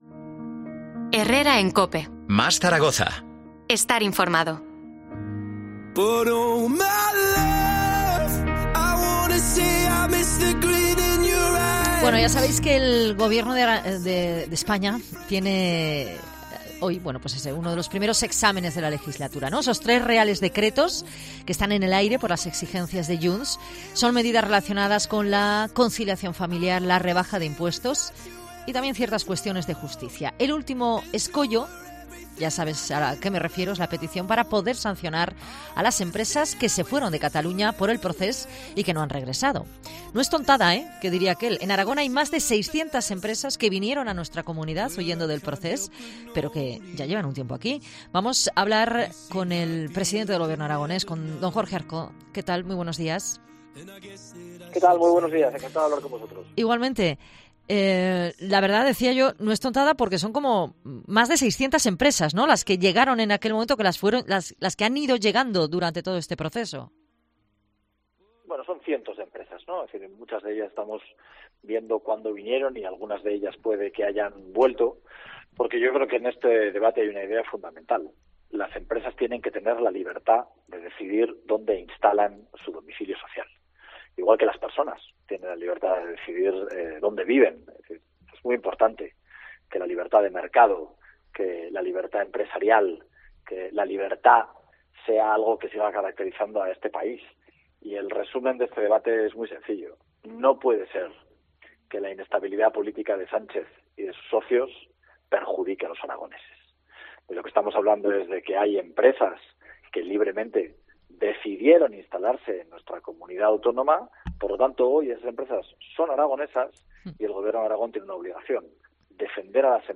En la entrevista, Azcón ha elogiado también la figura de César Alierta, fallecido este miércoles a los 78 años.